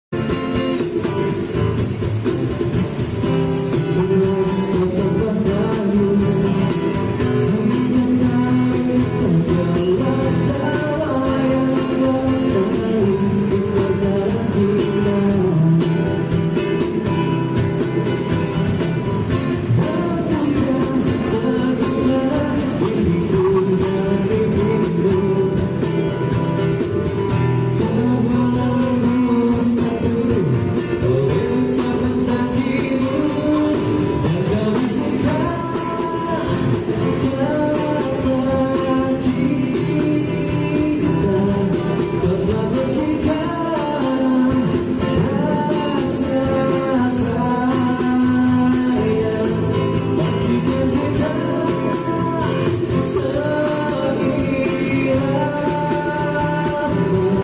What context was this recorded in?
hiburan live akustik silahkan DM